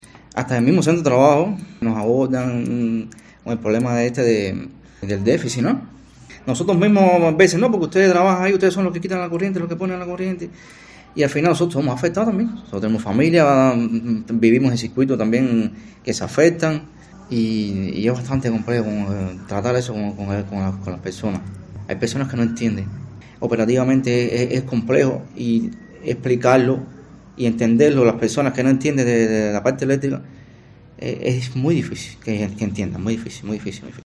Decidida a poner voz a lo que no encuentra luz me dirigí a la entidad sureña con la grabadora lista y el verbo afilado para indagar en explicaciones ya ofrecidas pero no bien comprendidas ni deseadas por buena parte de los cienfuegueros quienes, en los últimos tiempos, una, otra y otra vez, recibimos el alba y despedimos el ocaso sólo con la energía del gran astro.